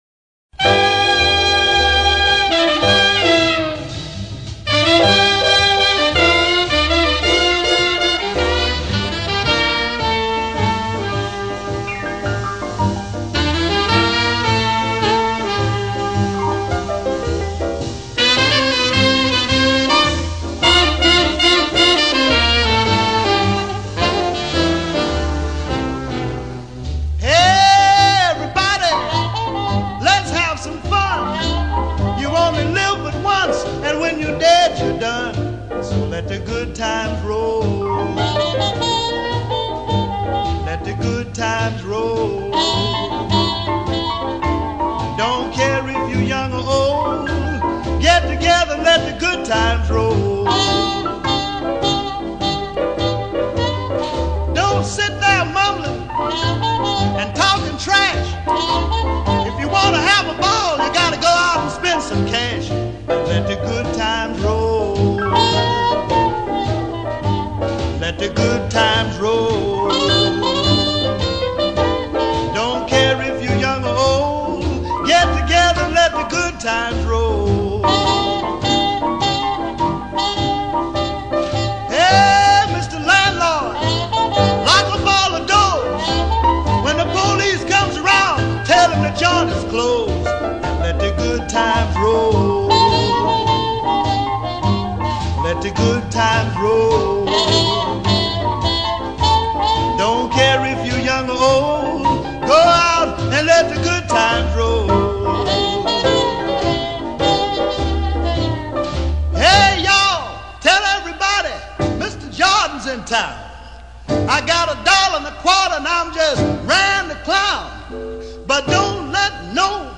Jazz Blues Para Ouvir: Clik na Musica.